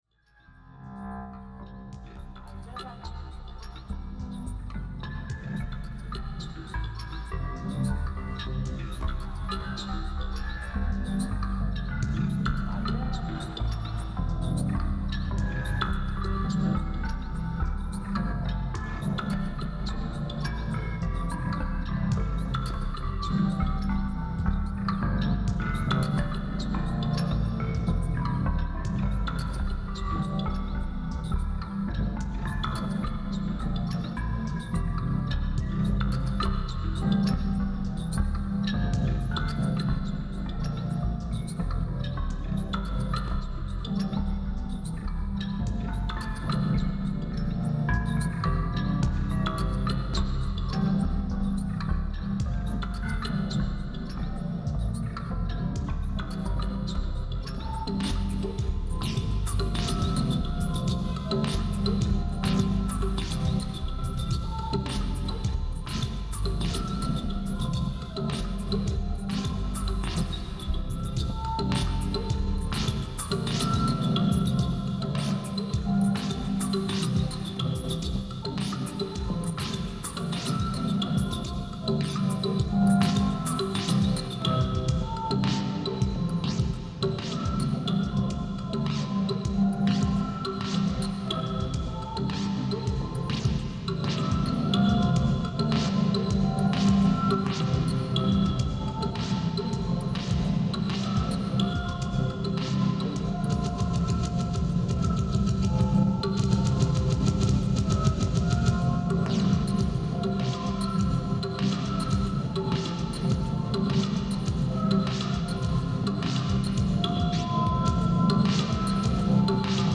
more mlre exercises: (mistakes included)